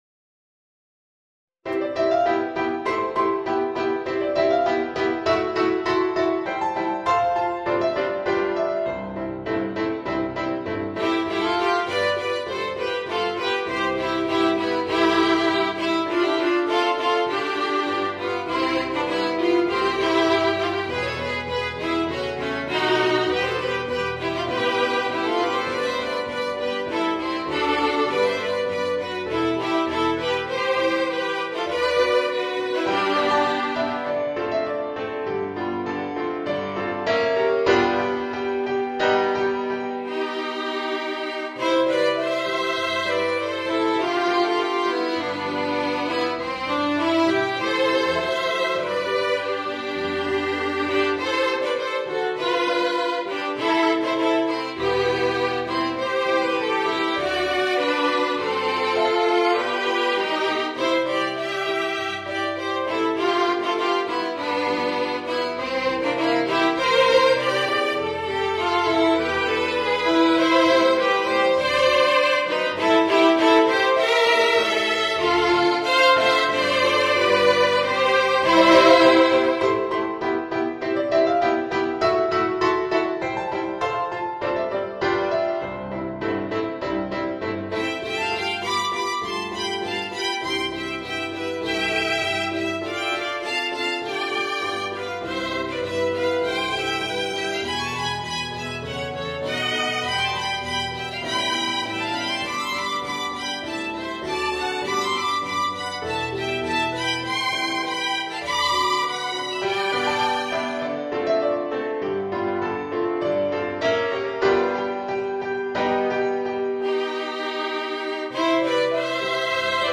arranged for Violin Duet with Piano Accompaniment.
[Key: C Major]